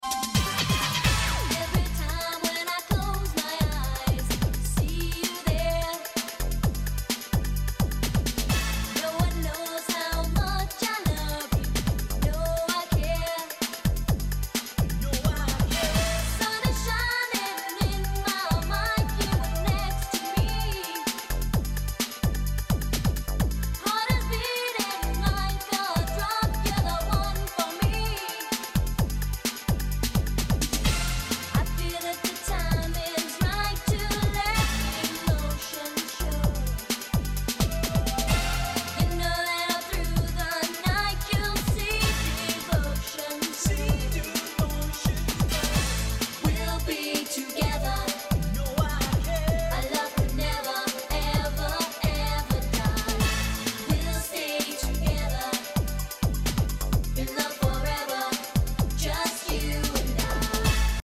Latin hip hop song